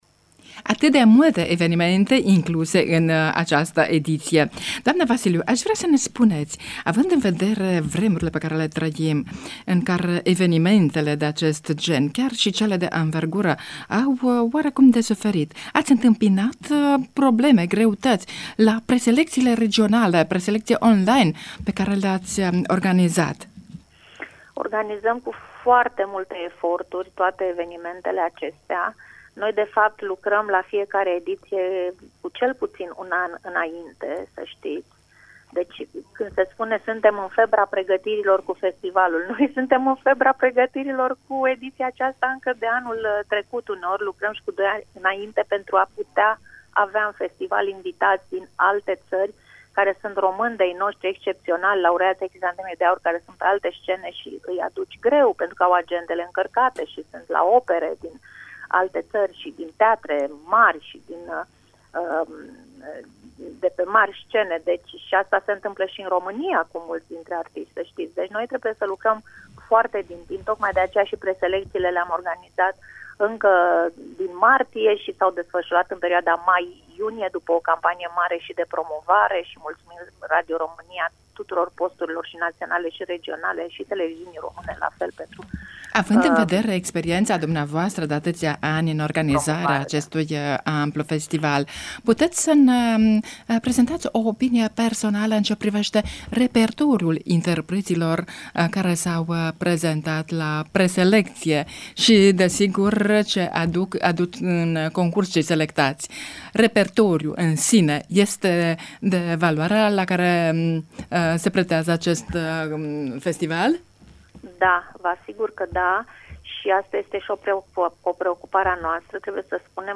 Fragment din interviul